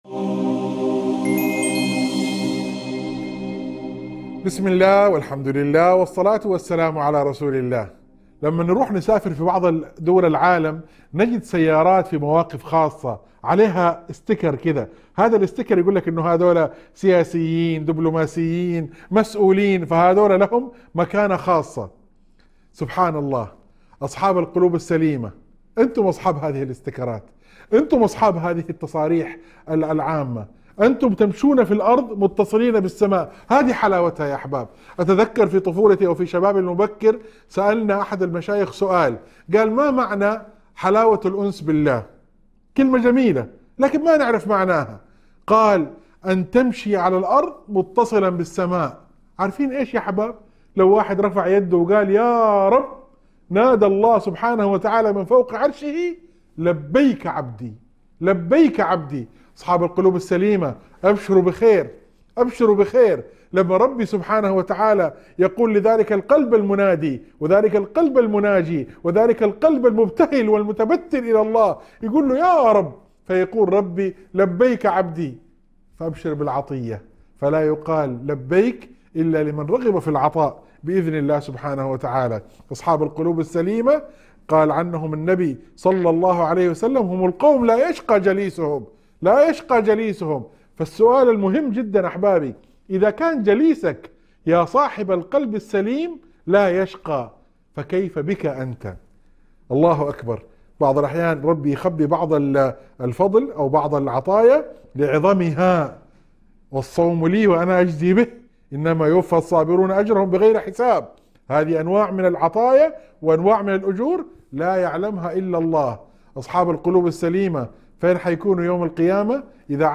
موعظة مؤثرة تذكر بفضل أصحاب القلوب السليمة الذين يناجون الله، وتؤكد على استجابة الدعاء وعظم الأجر عند الله. تلفت النظر إلى نعمة الأنس بالله وكيف أن جليس الصالح لا يشقى، مع ذكر بعض الفضائل مثل الصوم.